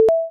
bling1.mp3